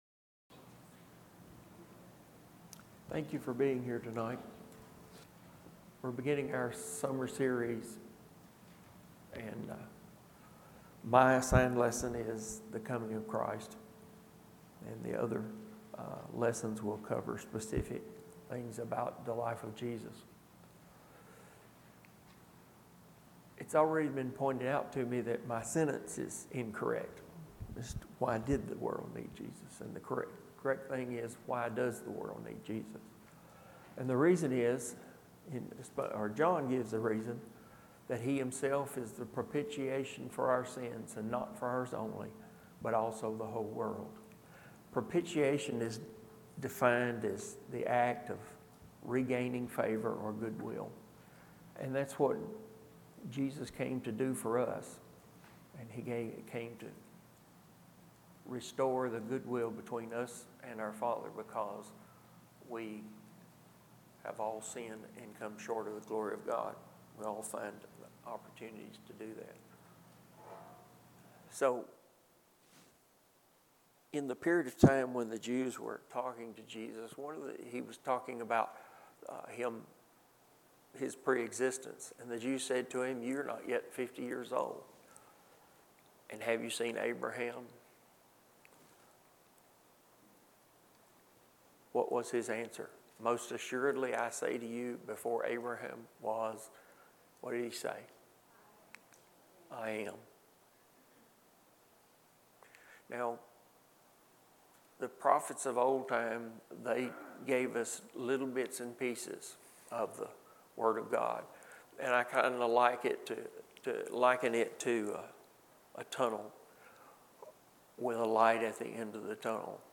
Summer 2022 The Life and Teachings of Jesus Service Type: Midweek Bible Class « Walking Through the Bible